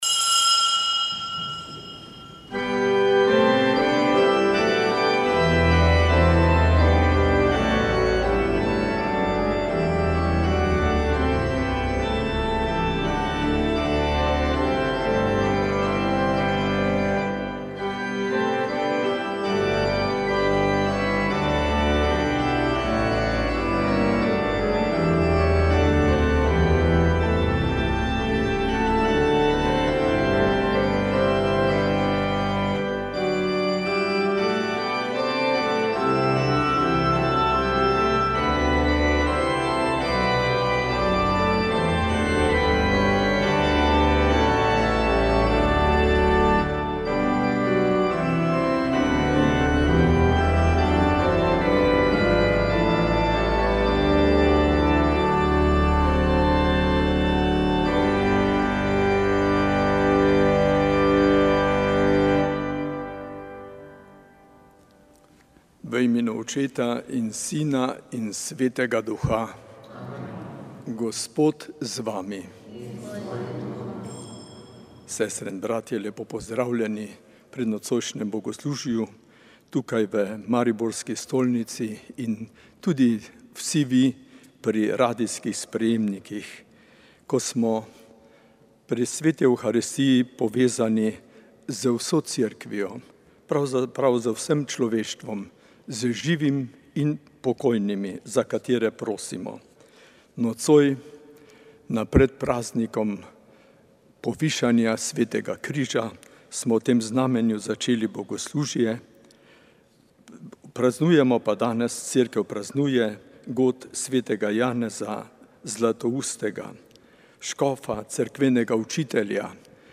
Sveta maša
Sv. maša iz stolne cerkve sv. Nikolaja v Murski Soboti 31. 7.